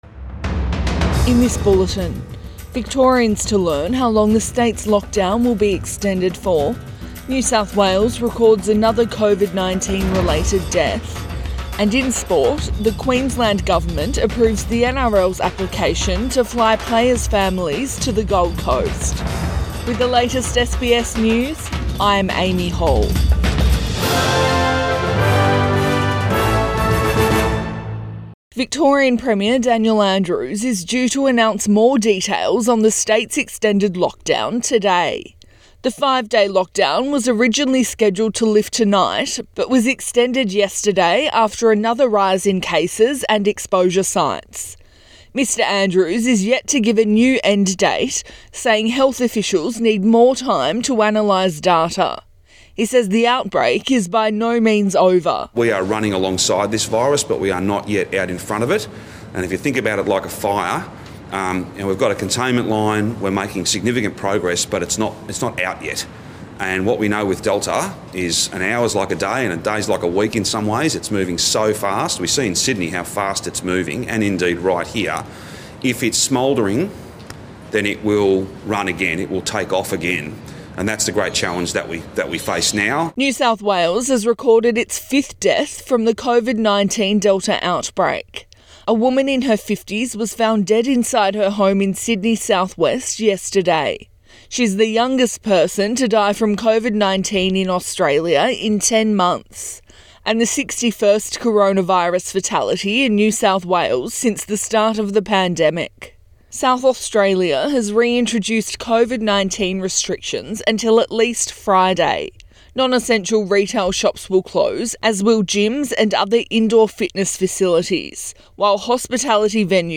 AM bulletin 20 July 2021